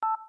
Index of /phonetones/unzipped/LG/GS390-Prime/DialPad sounds/Beep
DialPad8.wav